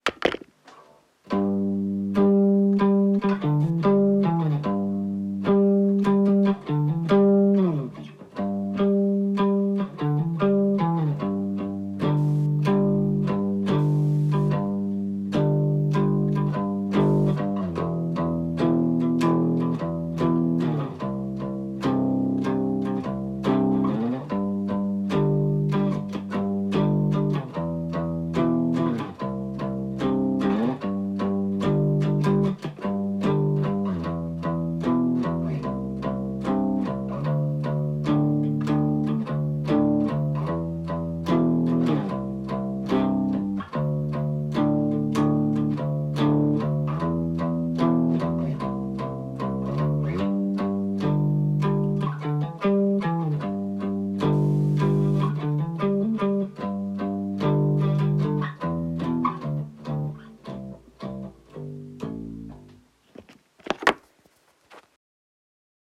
1-Bass-improv – Haynes Music Productions
1-Bass-improv.mp3